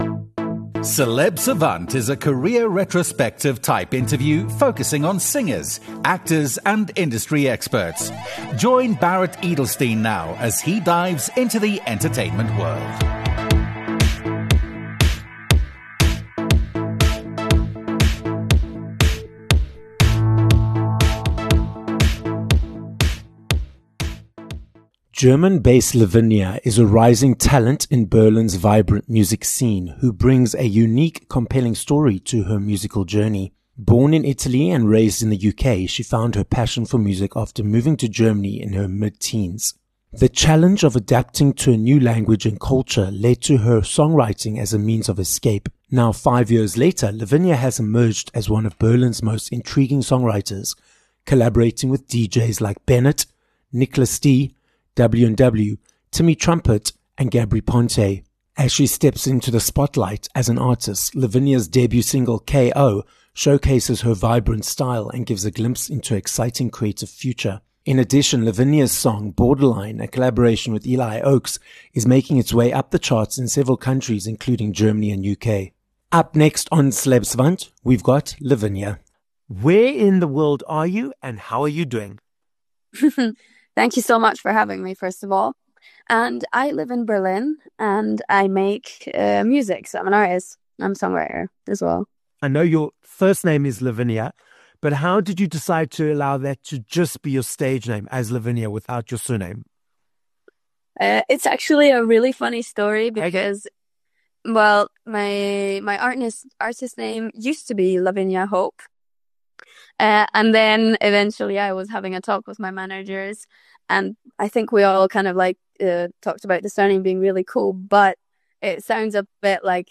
Each week we will have long-form career retrospective type interviews with celebrities namely, singers, actors and industry experts.